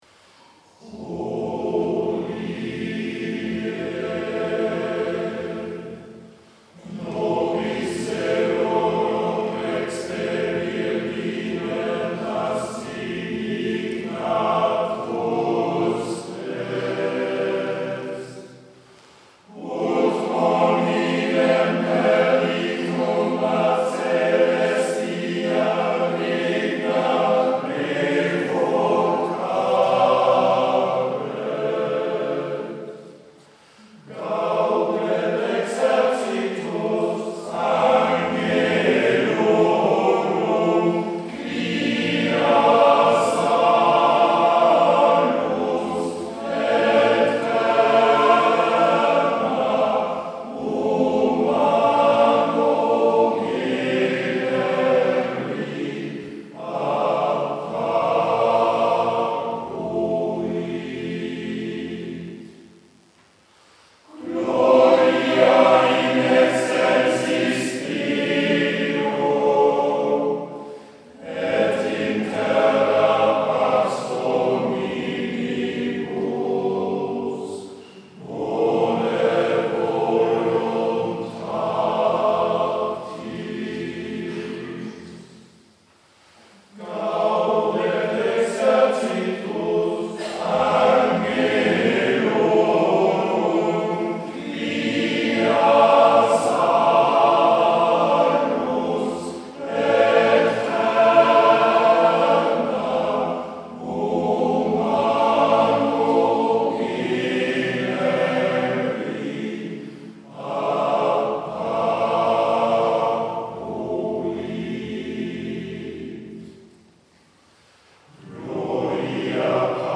Schlusslied_-_Responsorium_-_Heilige_Nacht_mp3